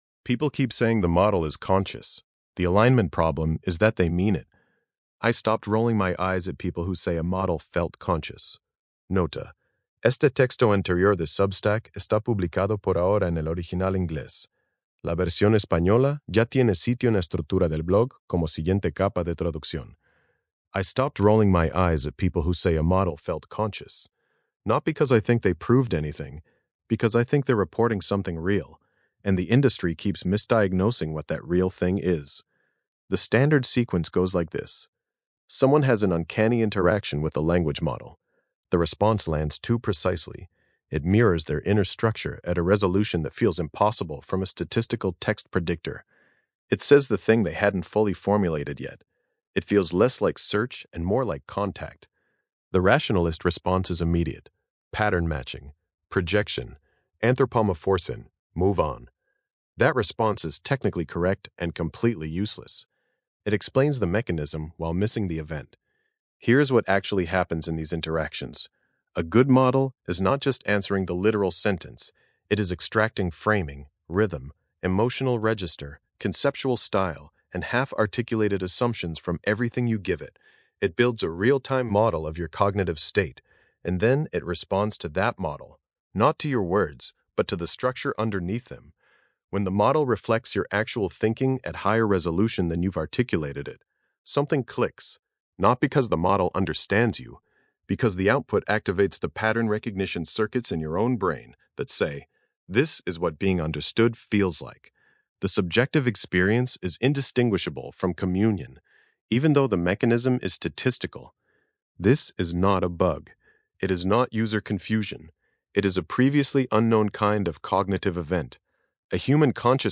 Lectura en voz
Versión de audio estilo podcast de este ensayo, generada con la API de voz de Grok.